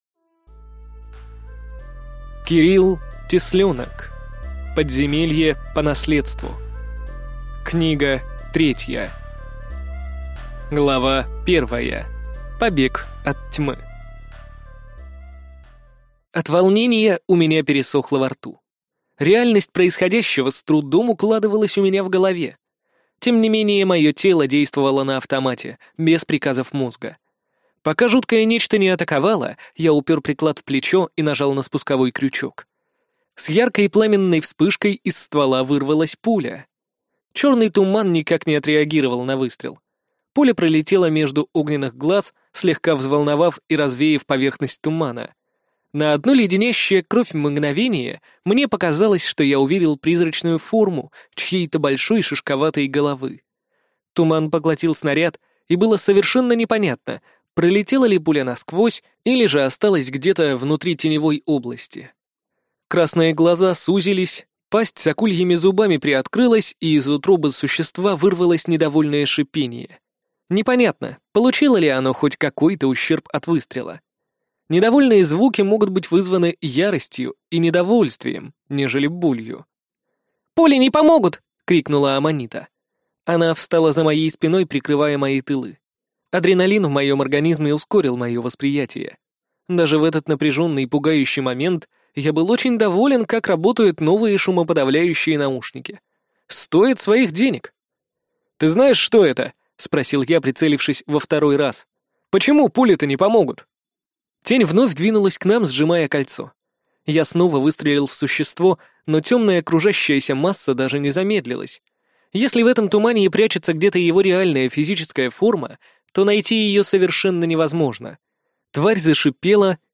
Аудиокнига Подземелье по наследству. Книга 3 | Библиотека аудиокниг